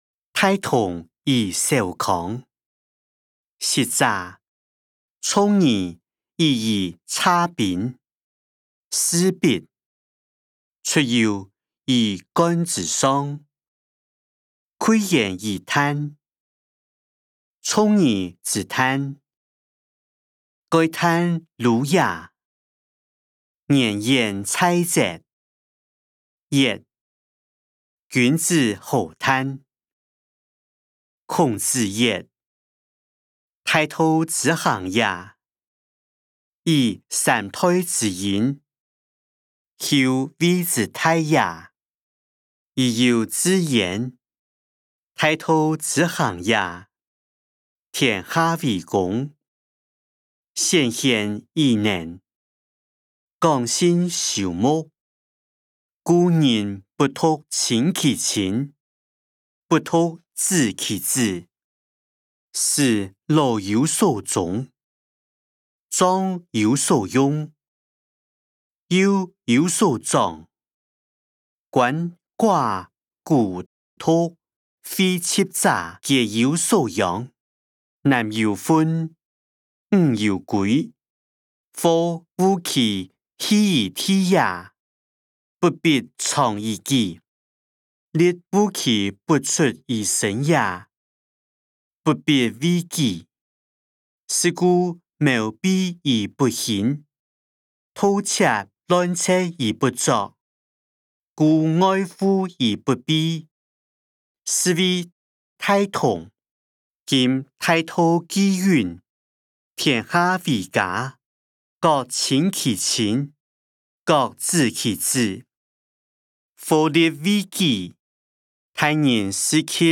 經學、論孟-大同與小康音檔(四縣腔)